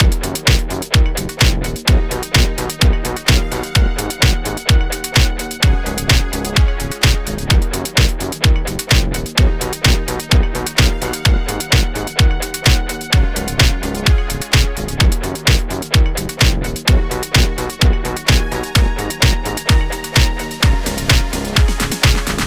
Music - Song Key
Gb Major
Music - Power Chords